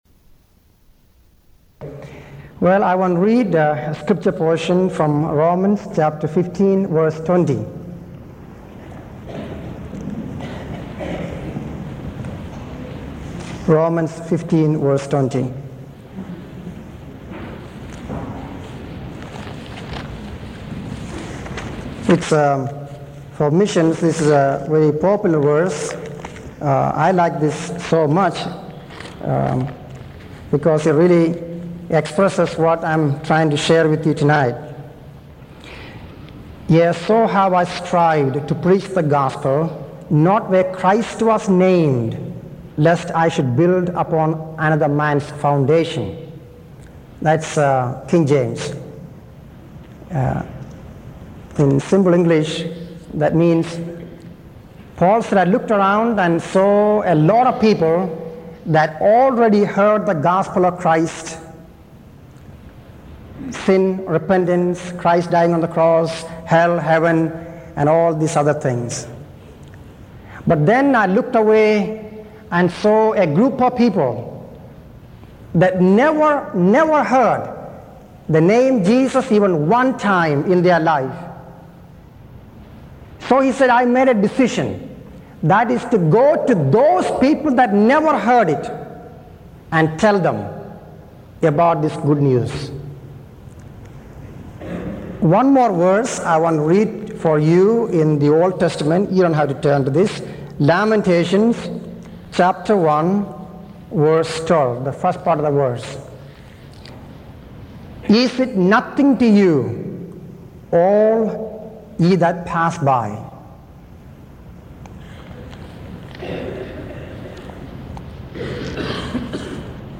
In this sermon, the speaker shares a personal experience of encountering a young girl in need and reflecting on his own privileged life.